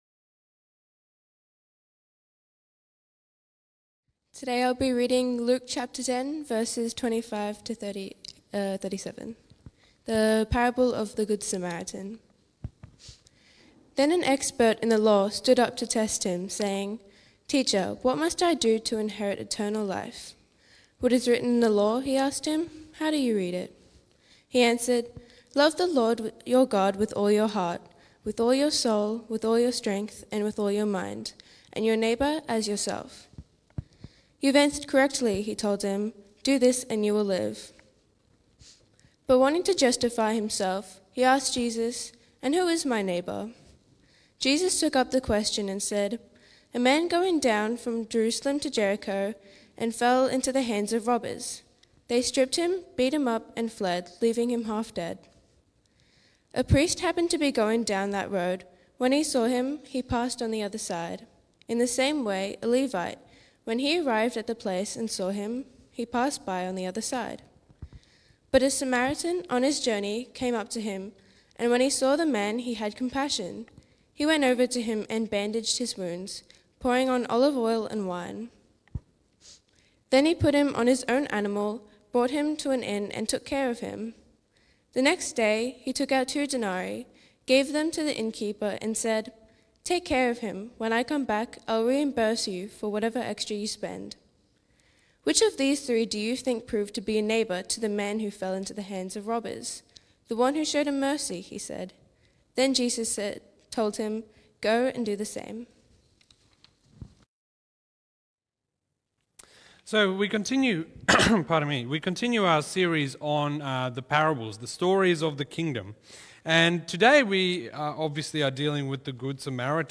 Sermons | Wonga Park Christian Reformed Church